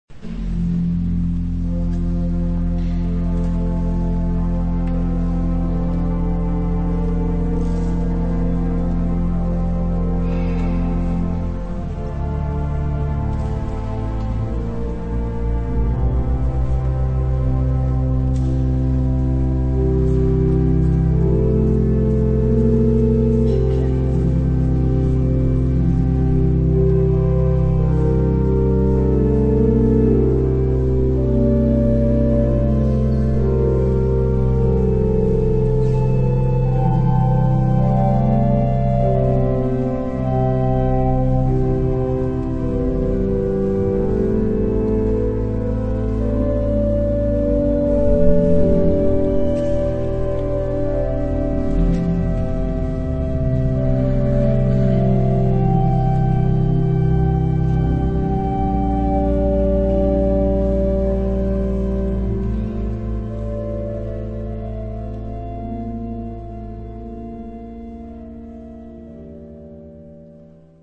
Organo1Lungo.mp3